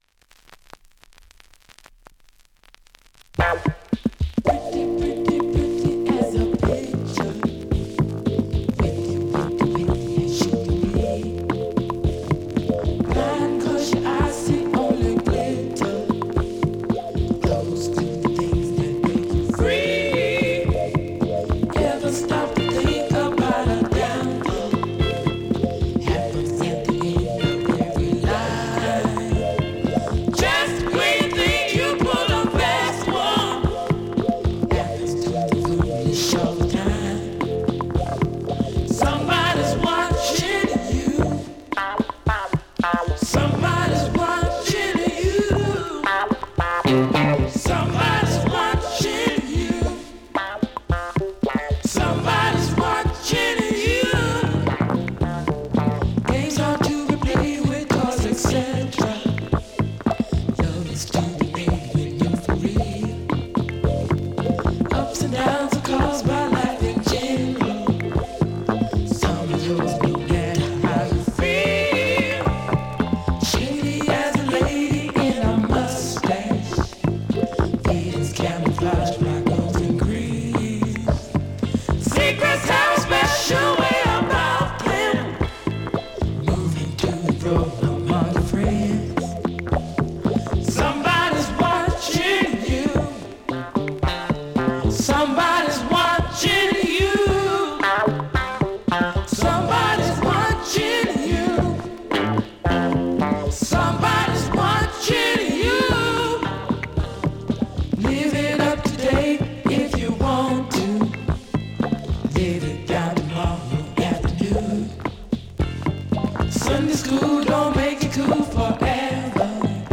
45 RPM現物の試聴（両面すべて録音時間）できます。